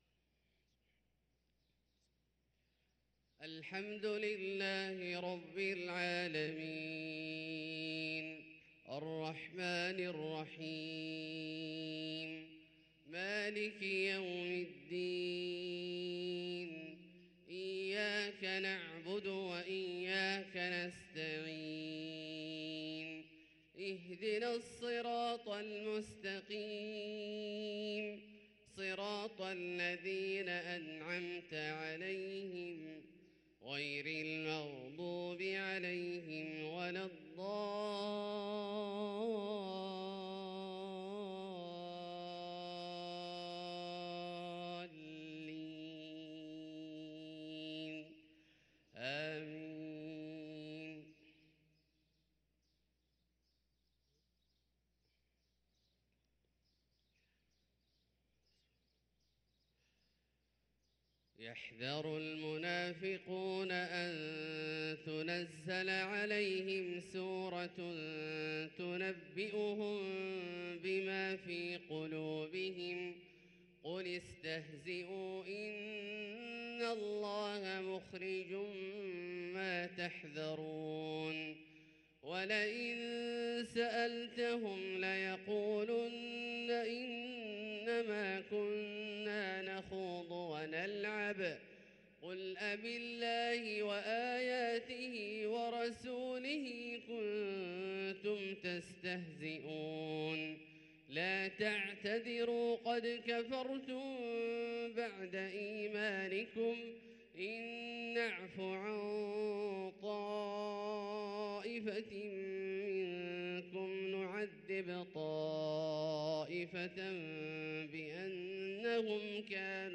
صلاة الفجر للقارئ عبدالله الجهني 28 جمادي الآخر 1444 هـ